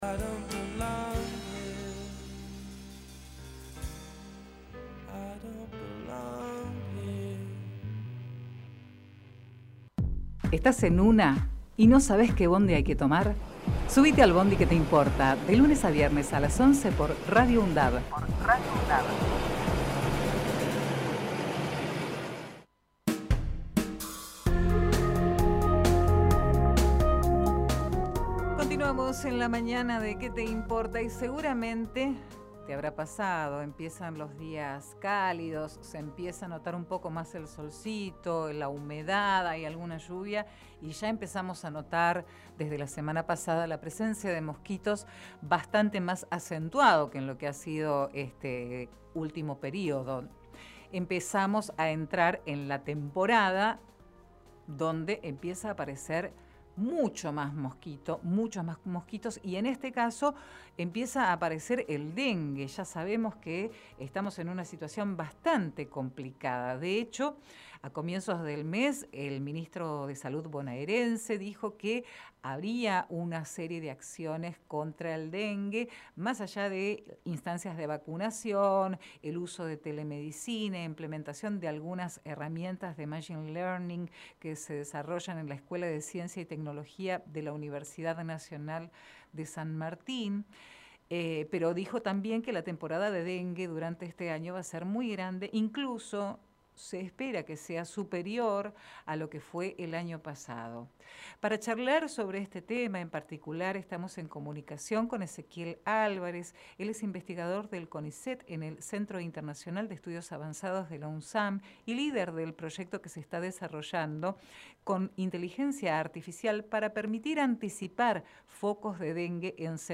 Compartimos la entrevista realizada en "Que te importa?!"